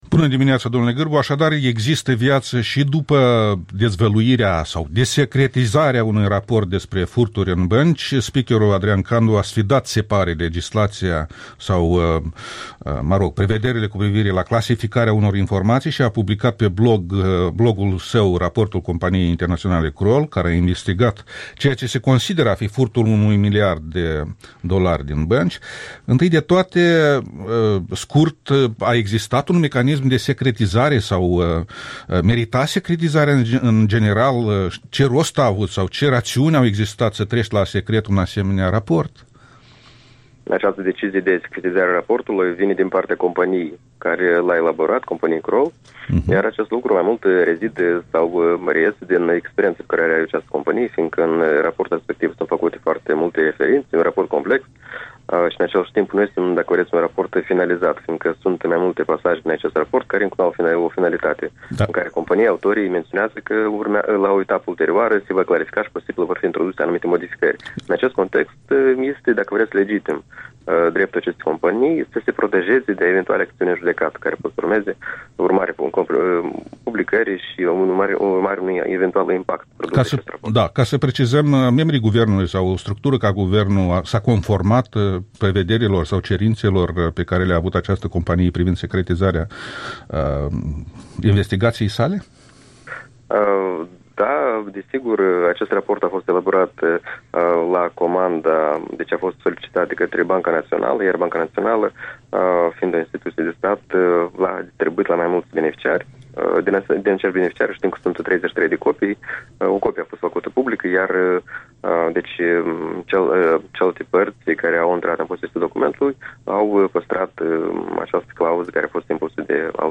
Interviul dimineții cu un expert economic de la Institutului Economiei de Piață.